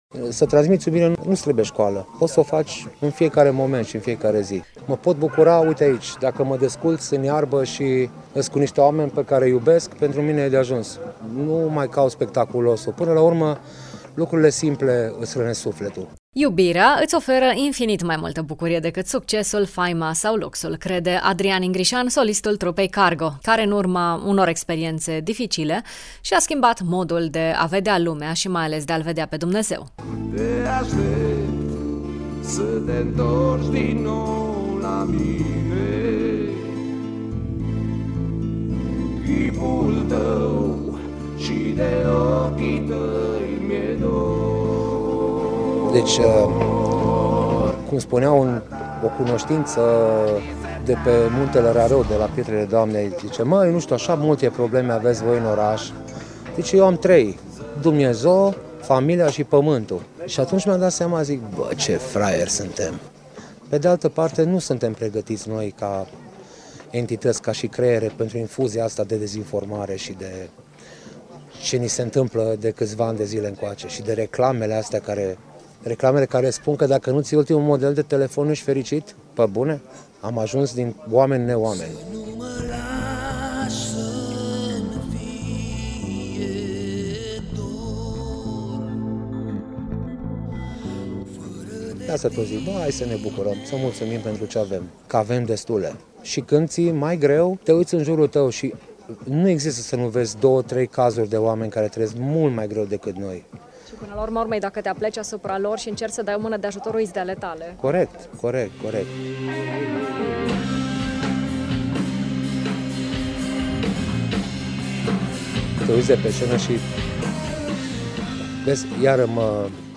Adrian Ingrișan, solistul trupei Cargo: